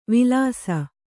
♪ vilāsa